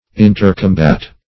\In`ter*com"bat\